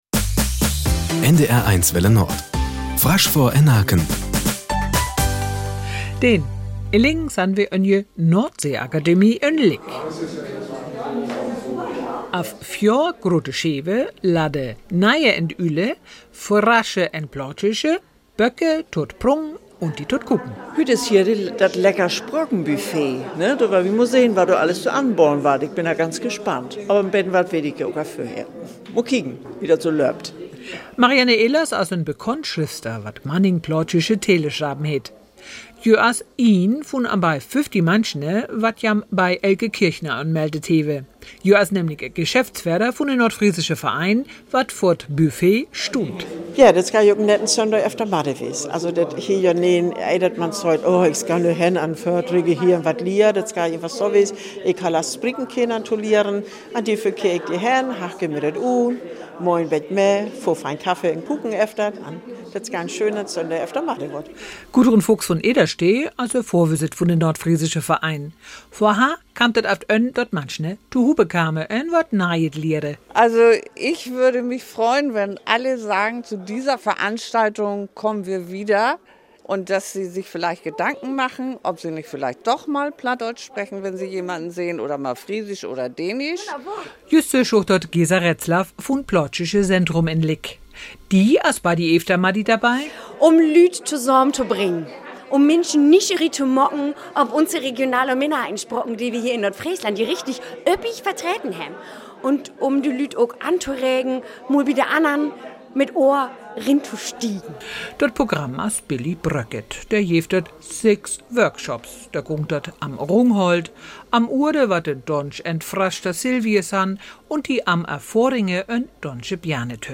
Nachrichten 06:00 Uhr - 26.09.2024